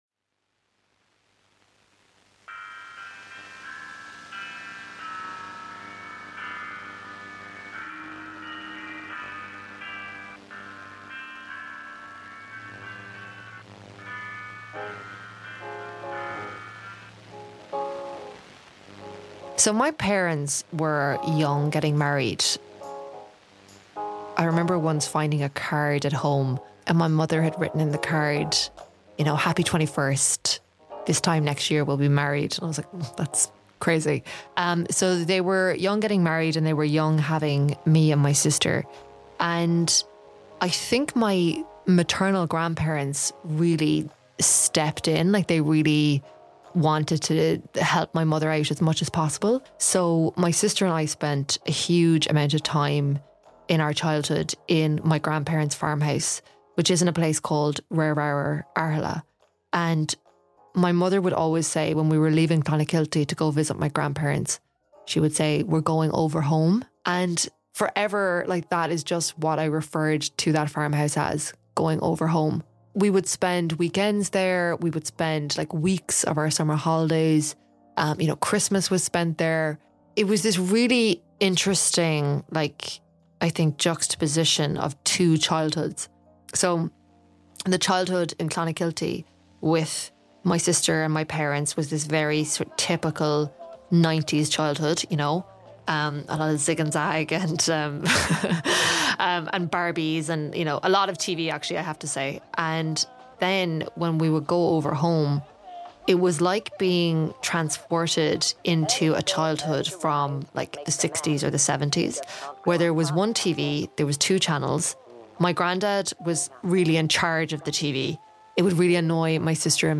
Headliner Embed Embed code See more options Share Facebook X Subscribe Growing up, Louise felt she had two childhoods: one full of the usual Nineties staples of homework and Home & Away at 6.30, but also one spent “over home” with her grandparents. It would be the time spent there that would lead her on a changing relationship with faith but also form a deep connection to her grandmother. Written and performed by Louise O’Neill.